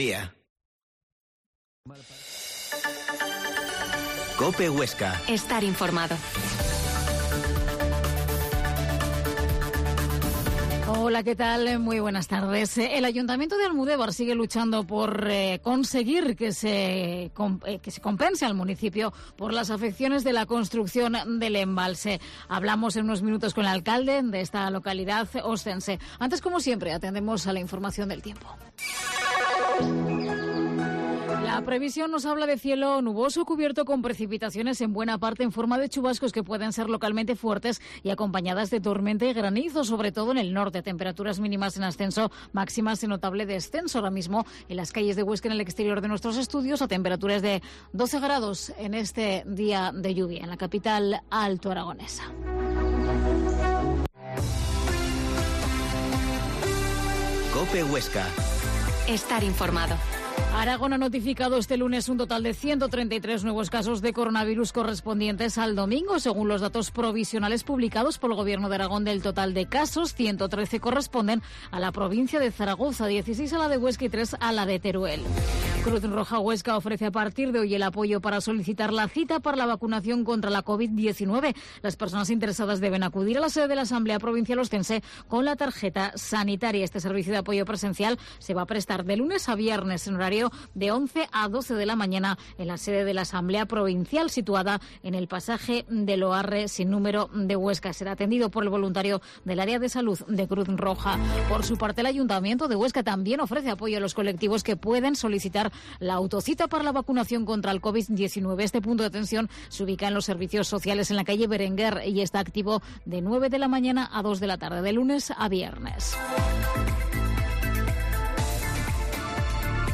Mediodia en COPE Huesca 13.20h Entrevista al alcalde de Almudévar,Antonio Labarta